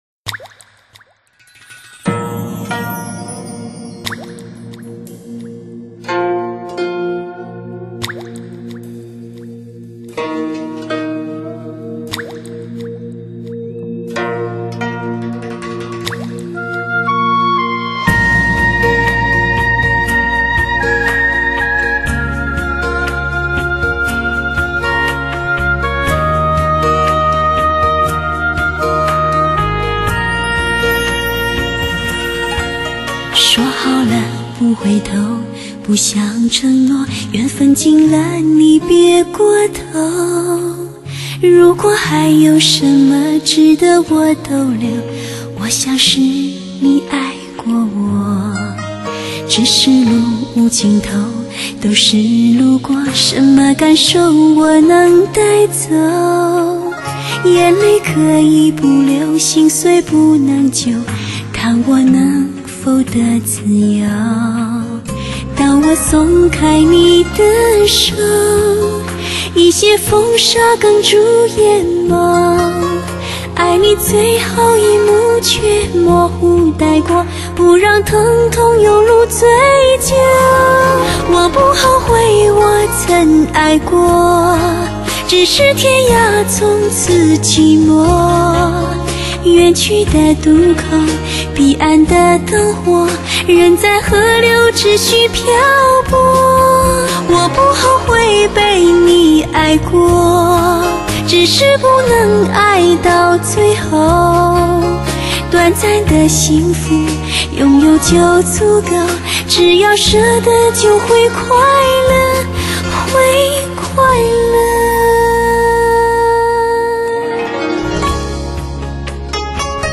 所属分类：音乐:发烧/试音:流行
让优美的歌声伴随你，专为汽车音响量身定做的HI-FI唱片，令你在飞驰中享受近乎无暇的美妙音乐。